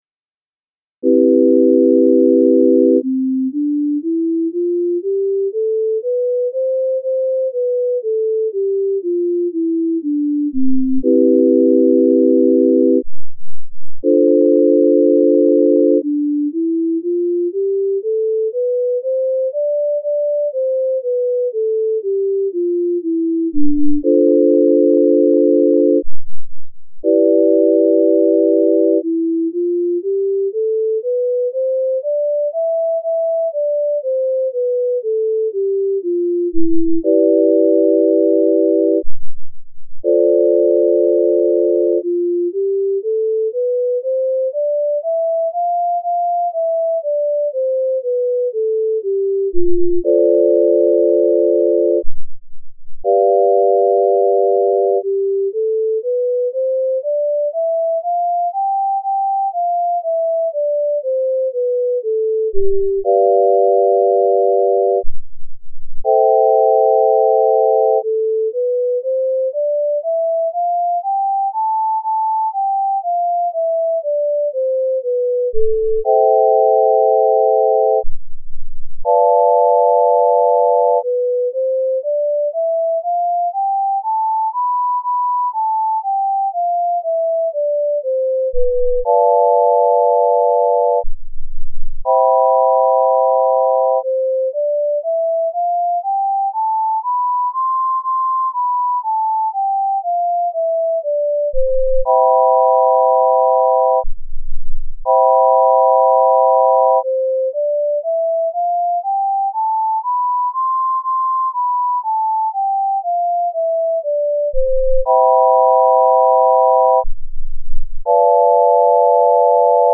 C-Major Scale Using the Tempered Scale - Left Ear & Using the Pythagorean Scale - Right Ear